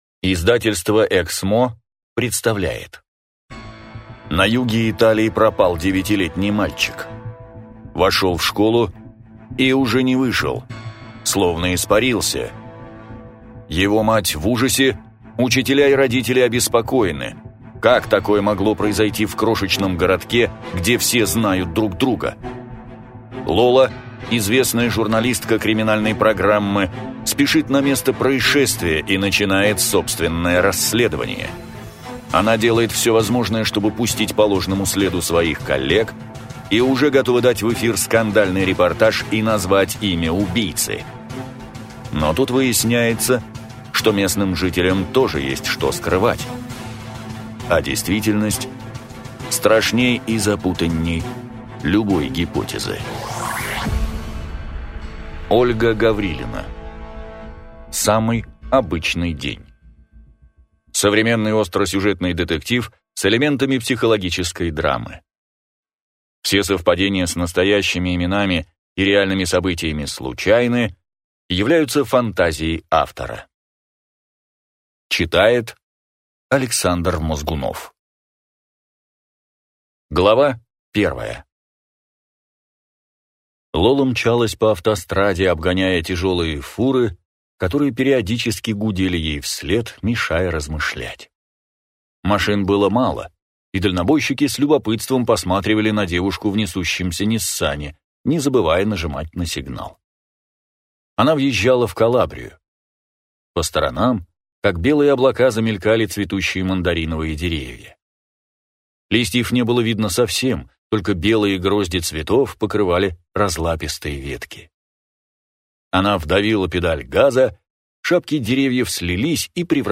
Аудиокнига Самый обычный день | Библиотека аудиокниг
Прослушать и бесплатно скачать фрагмент аудиокниги